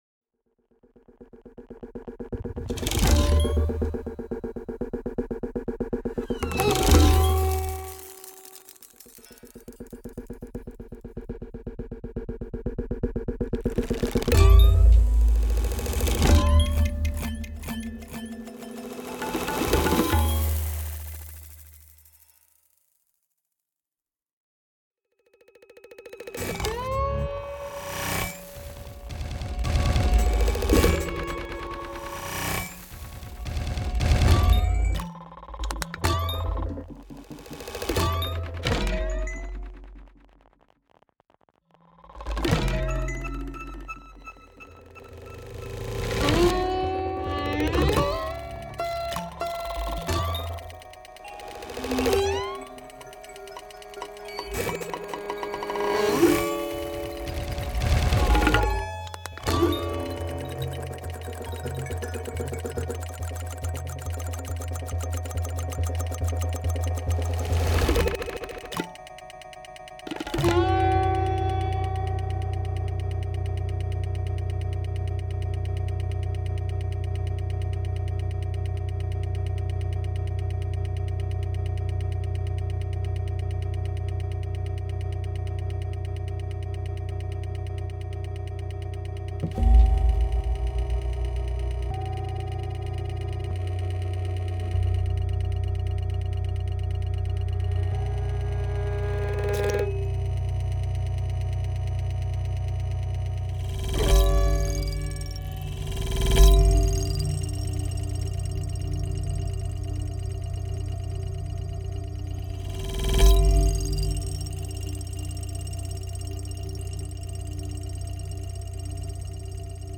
fixed media
sitar
tabla